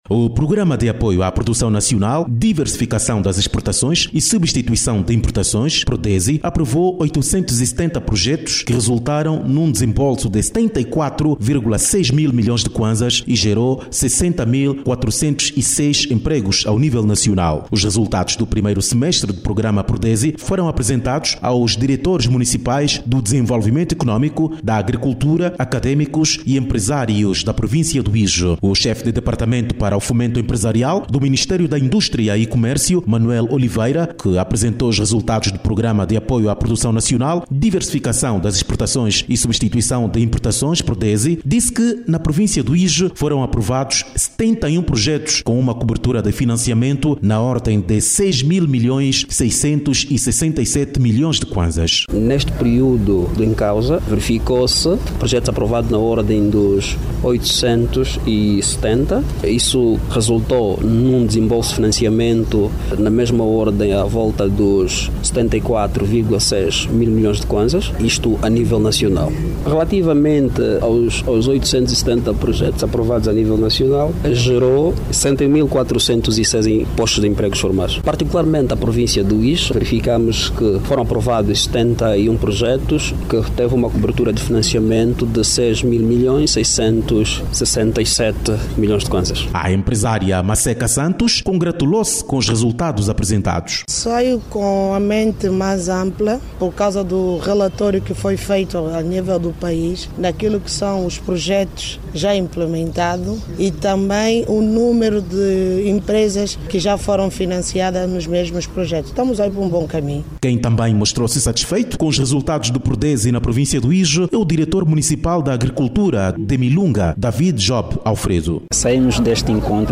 Vamos a reportagem